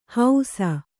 ♪ hausa